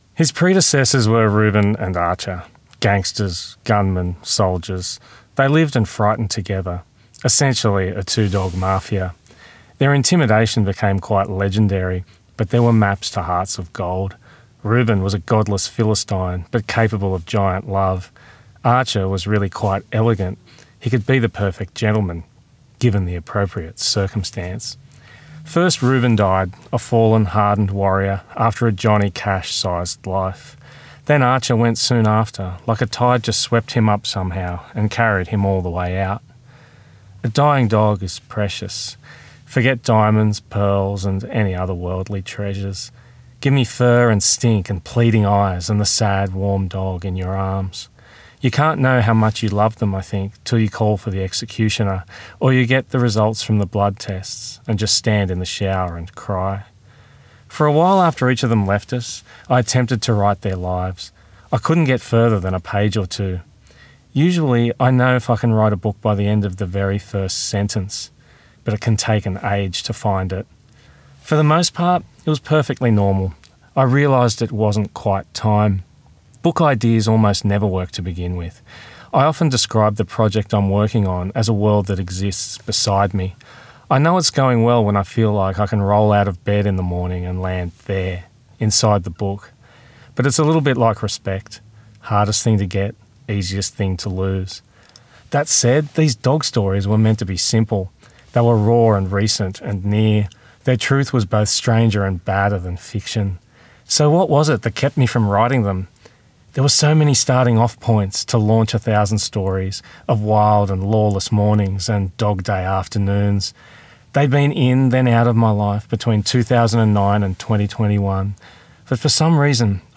Recorded at the Byron Writers Festival 2024
Markus-Zusak-read.mp3